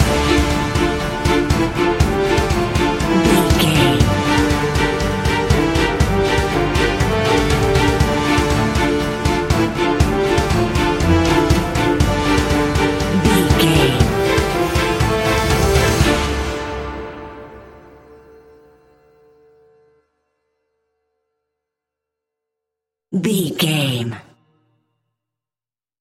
In-crescendo
Thriller
Aeolian/Minor
ominous
suspense
eerie
creepy
horror music
Horror Pads
horror piano
Horror Synths